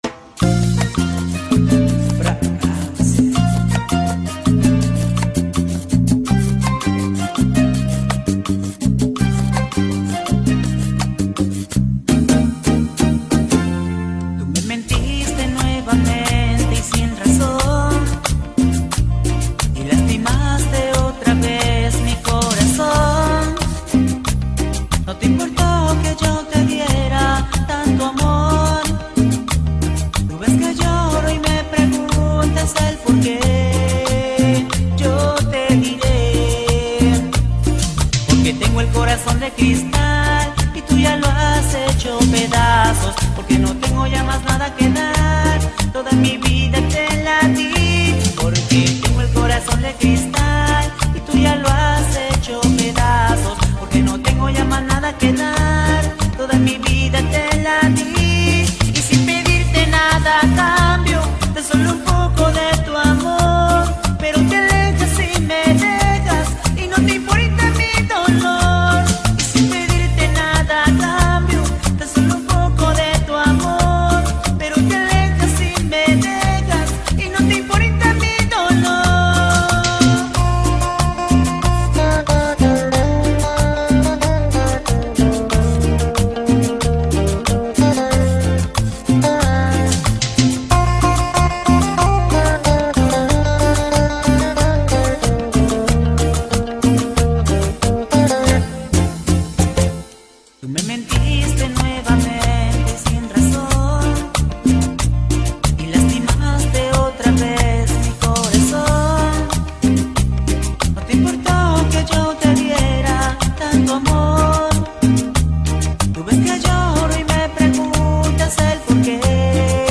música sonidera